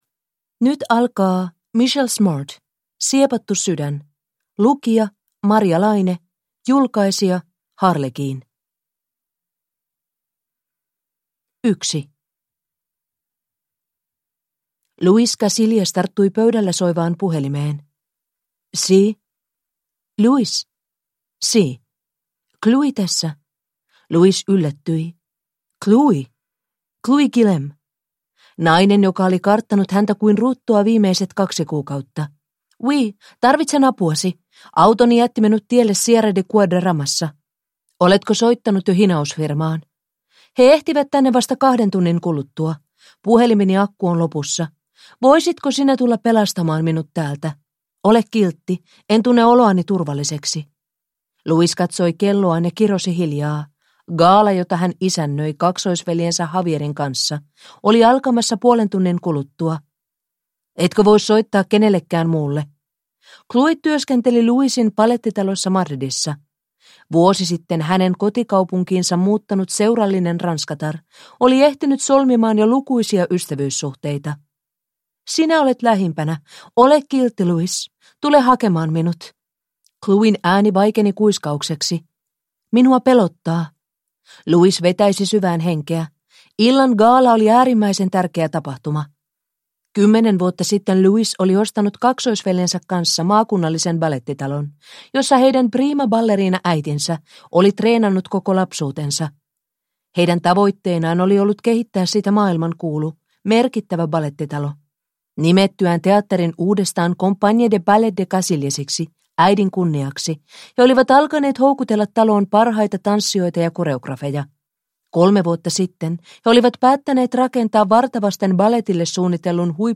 Siepattu sydän – Ljudbok – Laddas ner